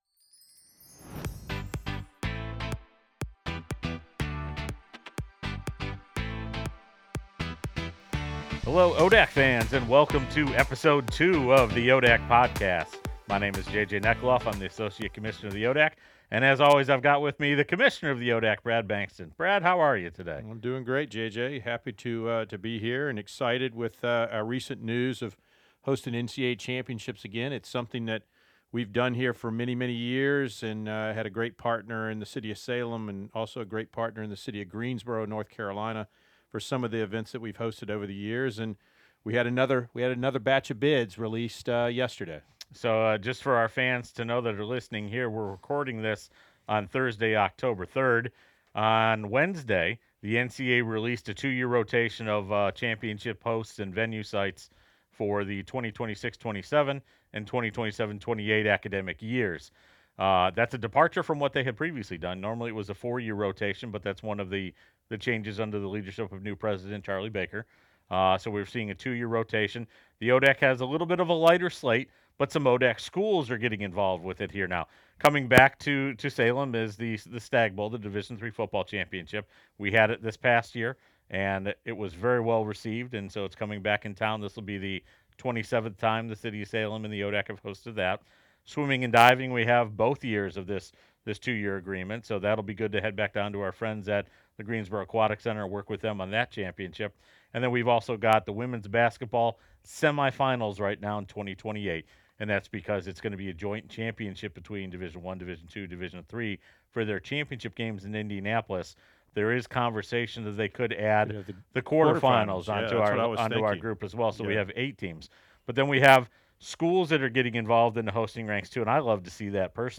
October 03, 2024 Two student-athlete interviews highlight the second episode of The ODAC Podcast.